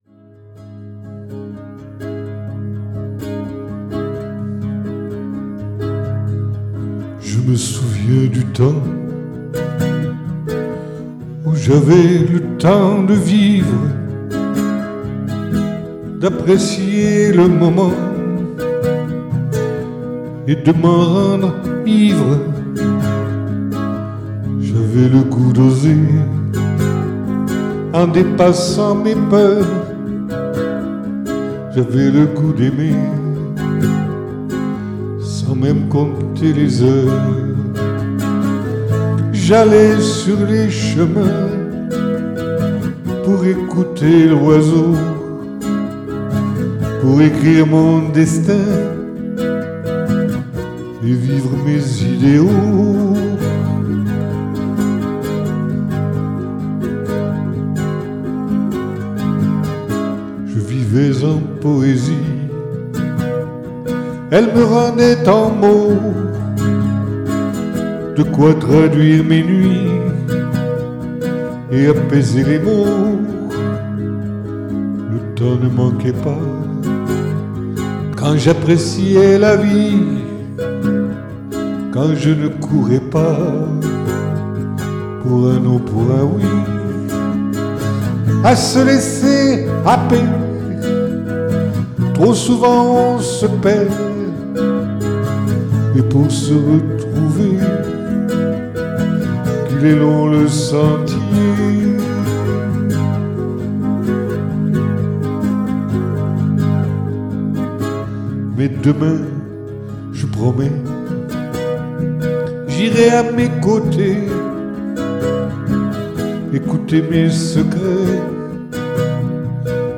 [Capo 4°]